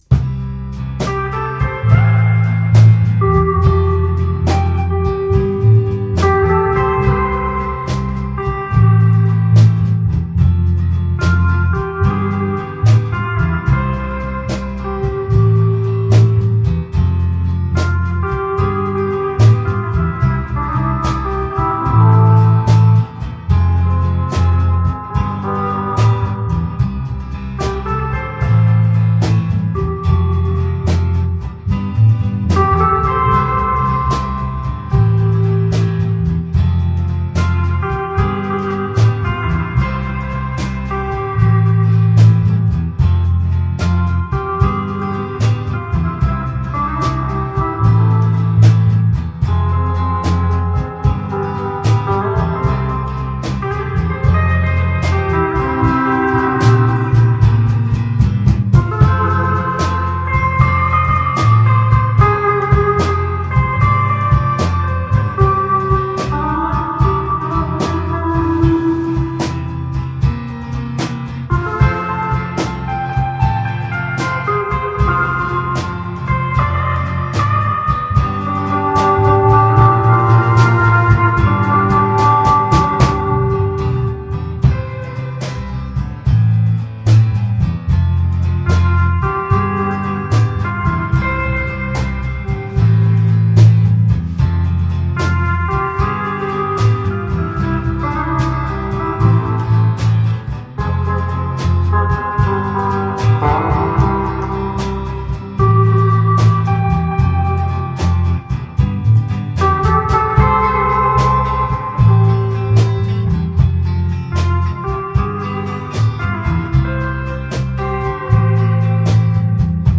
Guitare solo
Guitare rythmique
Guitare basse
Batterie
Chanteur Les � couter